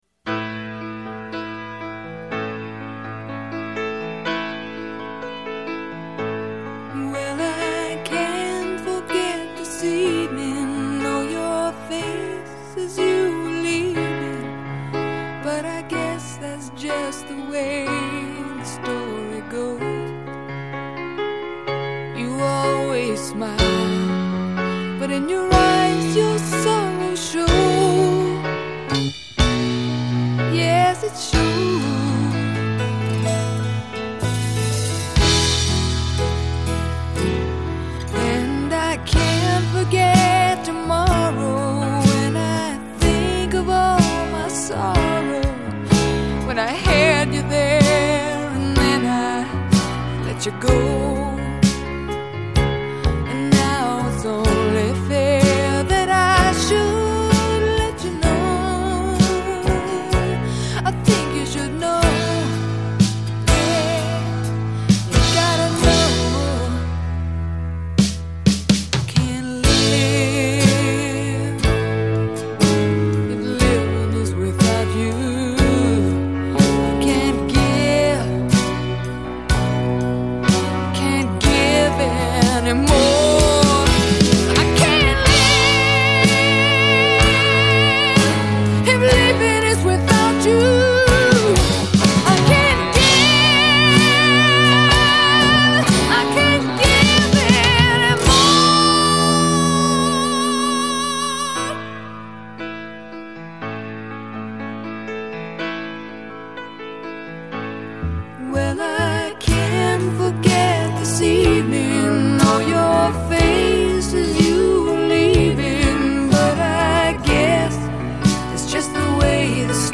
Жанр: hardrock